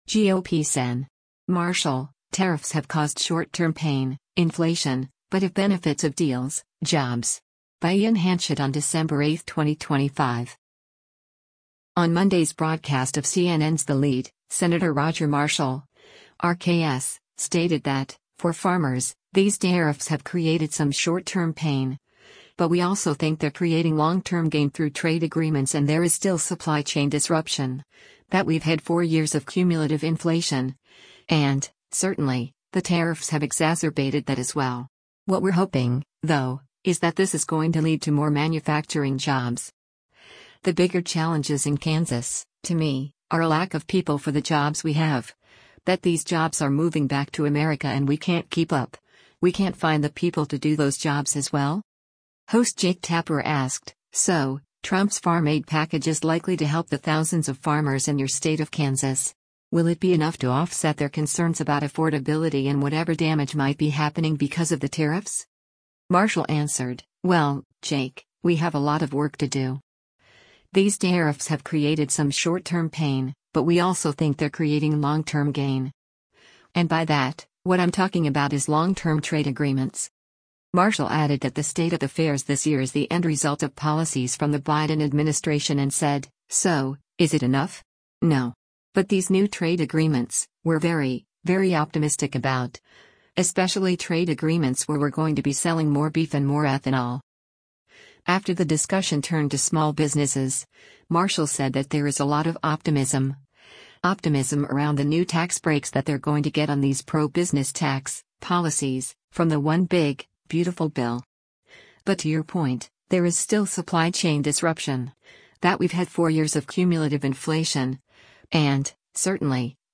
On Monday’s broadcast of CNN’s “The Lead,” Sen. Roger Marshall (R-KS) stated that, for farmers, “These tariffs have created some short-term pain, but we also think they’re creating long-term gain” through trade agreements and “there is still supply chain disruption, that we’ve had four years of cumulative inflation, and, certainly, the tariffs have exacerbated that as well. What we’re hoping, though, is that this is going to lead to more manufacturing jobs. The bigger challenges in Kansas, to me, are a lack of people for the jobs we have, that these jobs are moving back to America and we can’t keep up, we can’t find the people to do those jobs as well.”
Host Jake Tapper asked, “So, Trump’s farm aid package is likely to help the thousands of farmers in your state of Kansas. Will it be enough to offset their concerns about affordability and whatever damage might be happening because of the tariffs?”